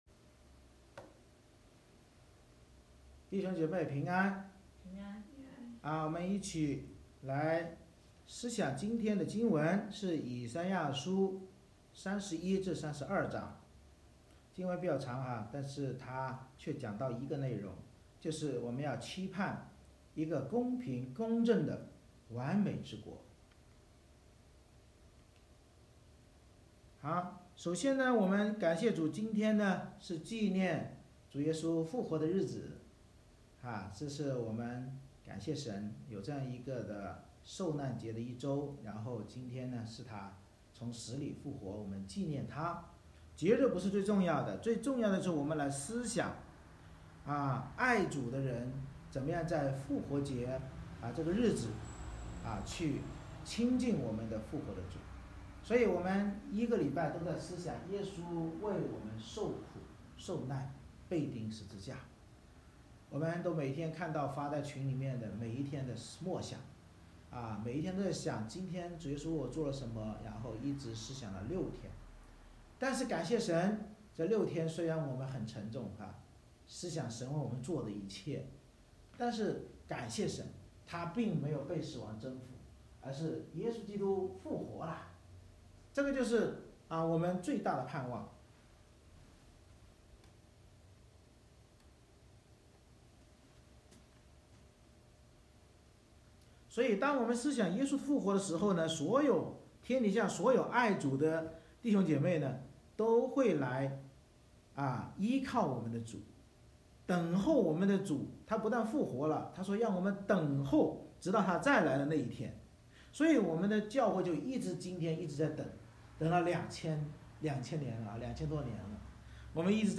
《以赛亚书》讲道系列 Passage: 以赛亚书 Isaiah 31:1-32:20 Service Type: 复活主日 神藉着先知预言悖逆子民的灾祸，教导我们：依靠与害怕世上的强权就必遭灾祸，但那不贪图世俗安逸而依靠等候弥赛亚公平公正国度的子民有福了。